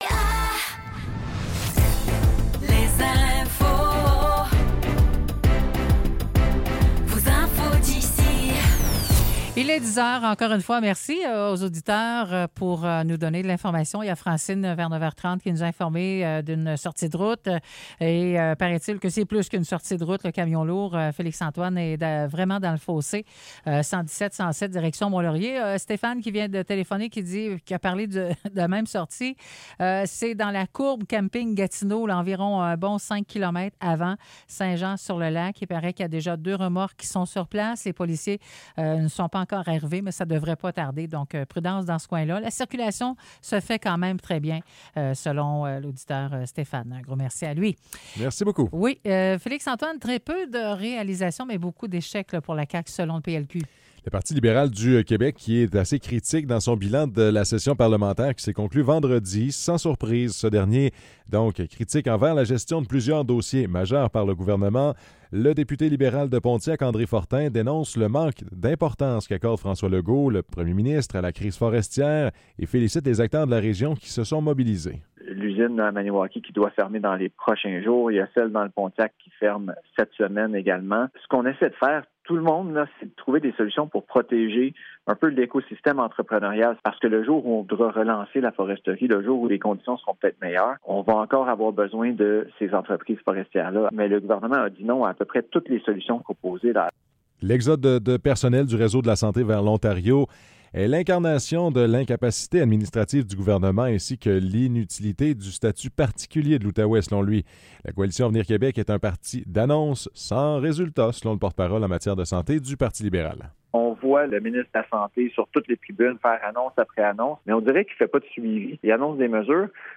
Nouvelles locales - 12 décembre 2024 - 10 h